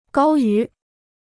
高于 (gāo yú) Kostenloses Chinesisch-Wörterbuch